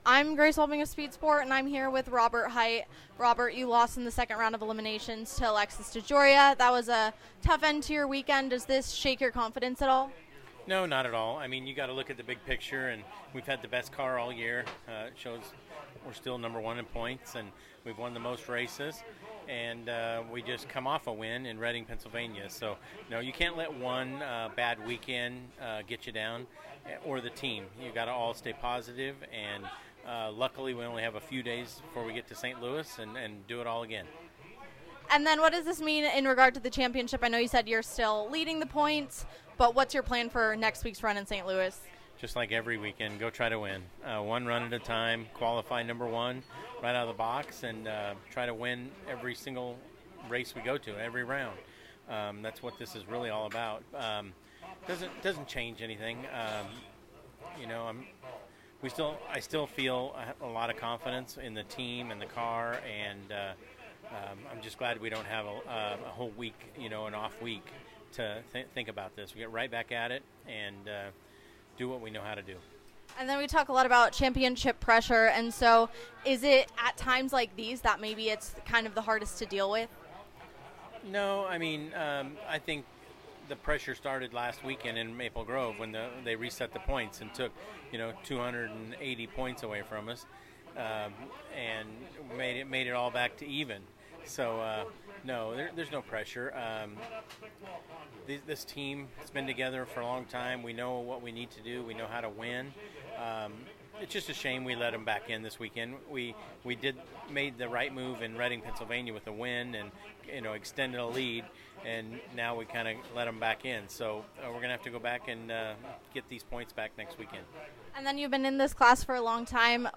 Listen to SPEED SPORT’s interview with Robert Hight from the NHRA Carolina Nationals.